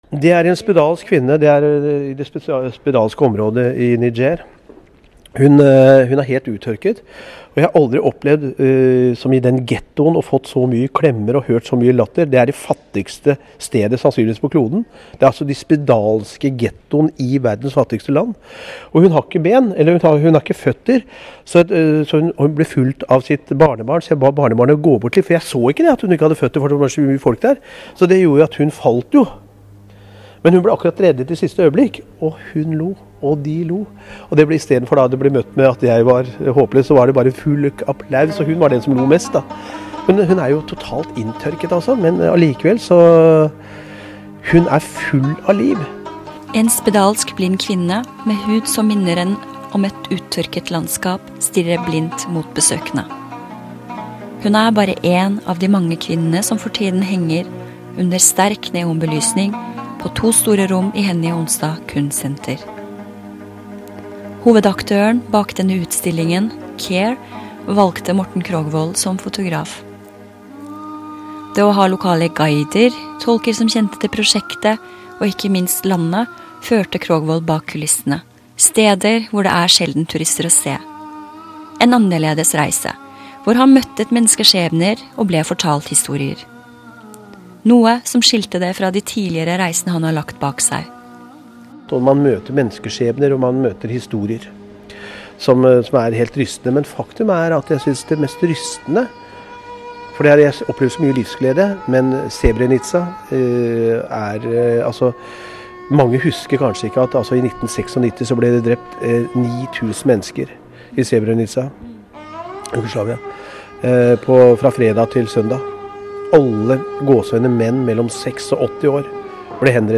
Intervjuet jeg deler her ble gjort i forbindelse med en utstilling på Hennie Onstad Kunstsenter på Høvikodden i 2004. Morten Krogvold hadde vært med på et kvinneprosjekt i regi av Care.